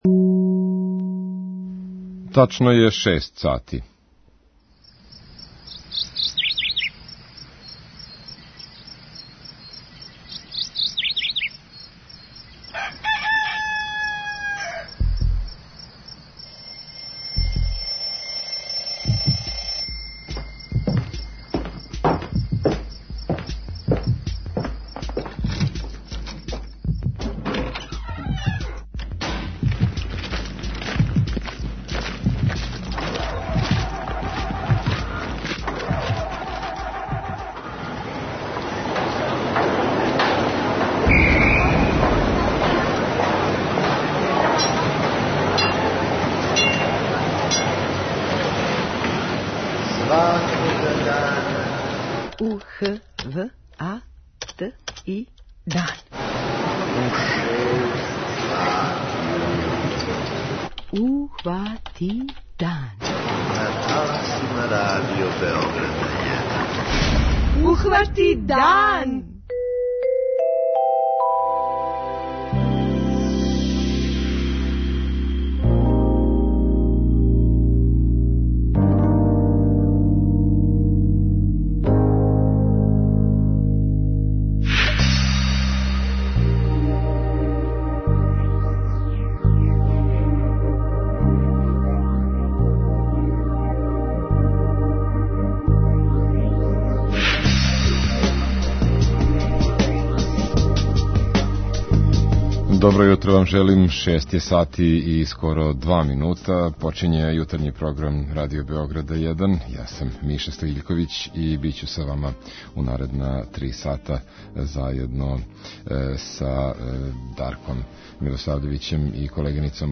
преузми : 32.36 MB Ухвати дан Autor: Група аутора Јутарњи програм Радио Београда 1!